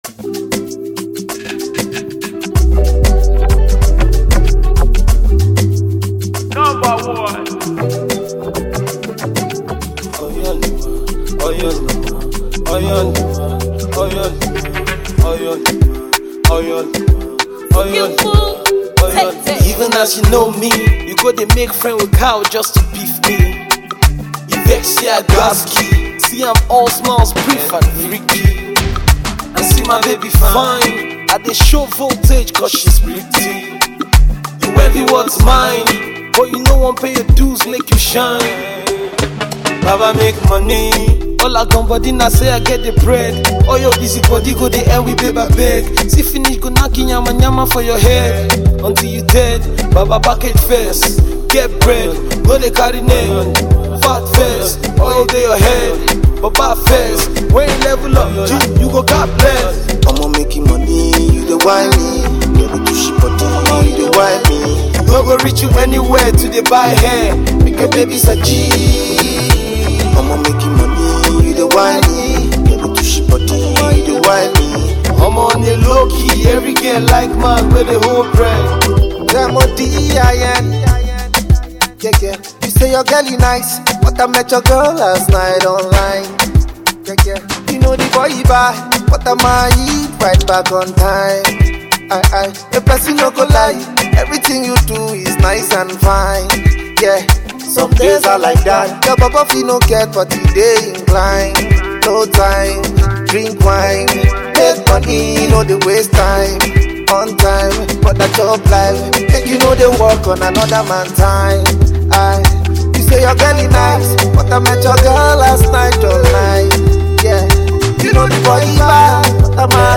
Nigeria Rapper,Singer and songwriter
fast raising nigeria singer and songwriter